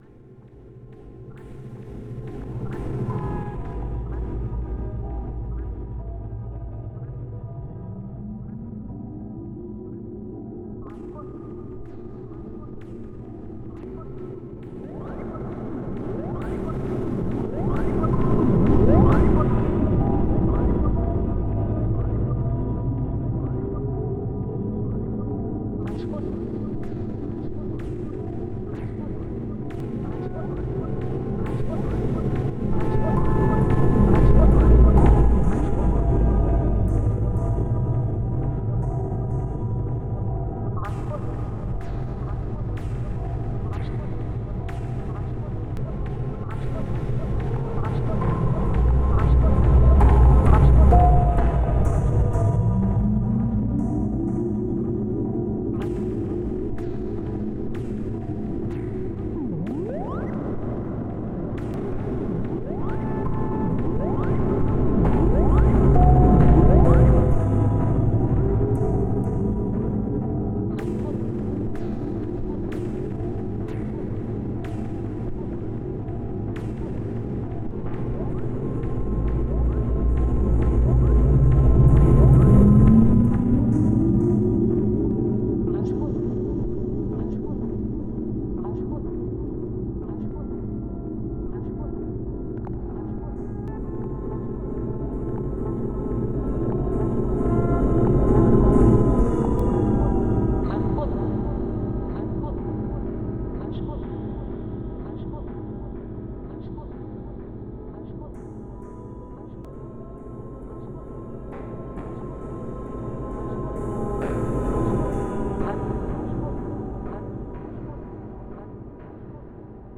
Genre: Industrial.